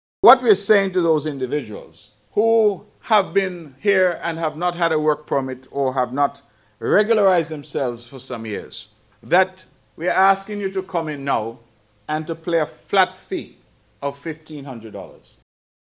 That was the voice of Premier of Nevis, Hon. Mark Brantley speaking on the Amnesty on arrears of payment for work permits, annual Residency and Visa extensions that has been granted to non-nationals since July 1st of this year.